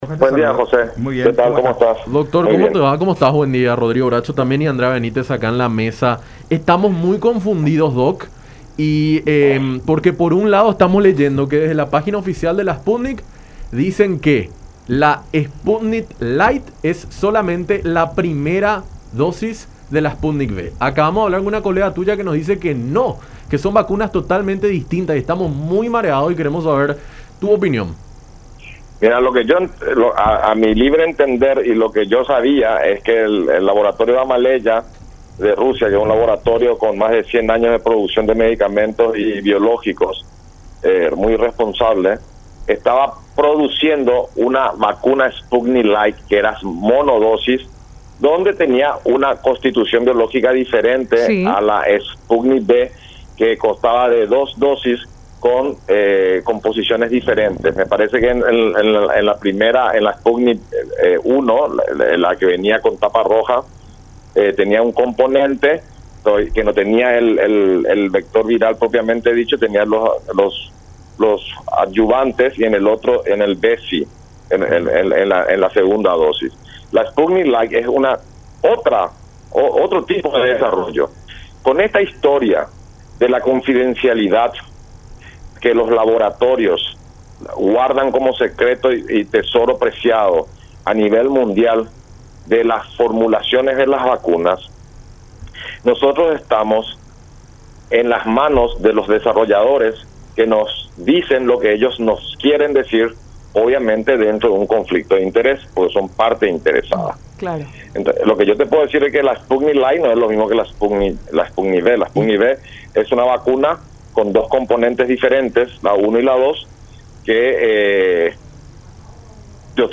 en conversación con Enfoque 800 a través de La Unión